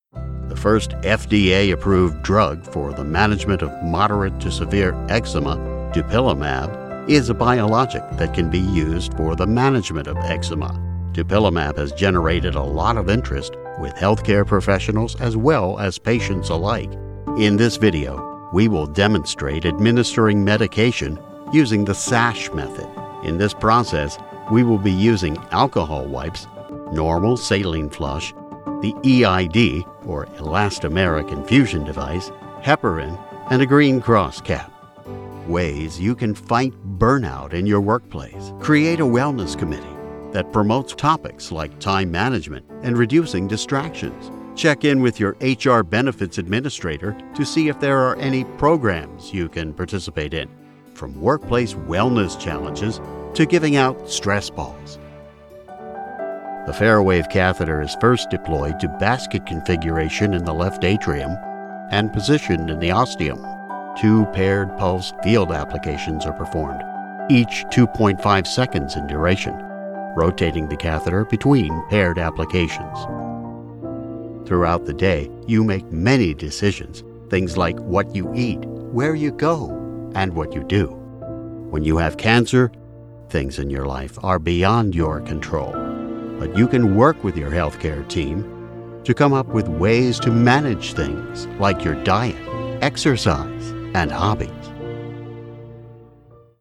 I'm an adult male baritone who's just as comfortable being conversational as authoritative, from friendly to firm, depending on what you need!
Voice Age
I have a broadcast-quality home studio in central Maryland.